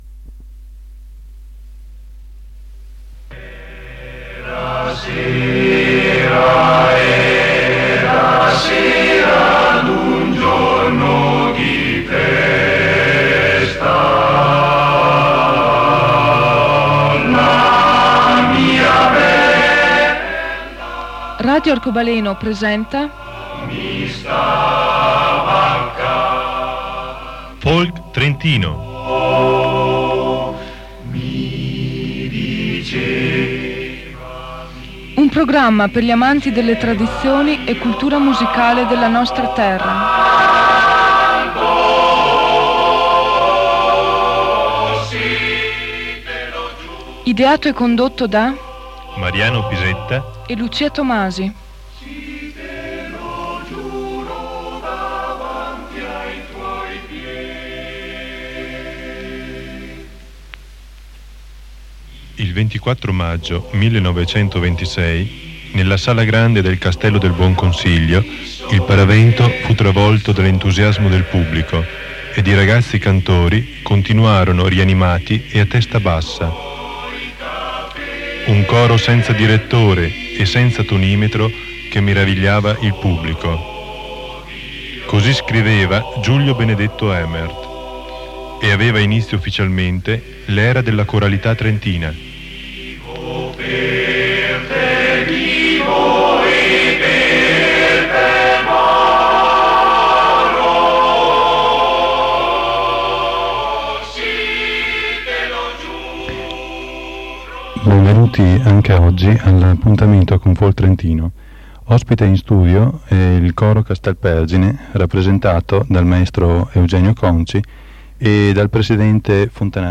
B.1.3.2 - «Folk Trentino». Programma radiofonico a Radio Arcobaleno con intervista